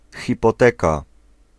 Ääntäminen
Ääntäminen France: IPA: /i.pɔ.tɛk/ Haettu sana löytyi näillä lähdekielillä: ranska Käännös Ääninäyte Substantiivit 1. hipoteka {f} Suku: f .